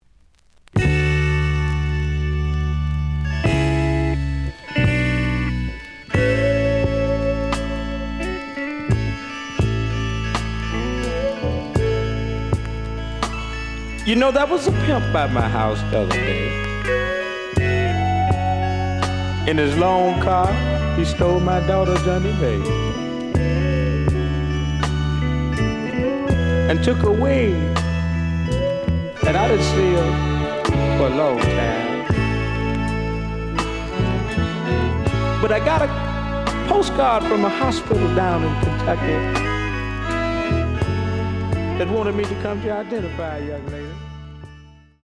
試聴は実際のレコードから録音しています。
●Genre: Funk, 70's Funk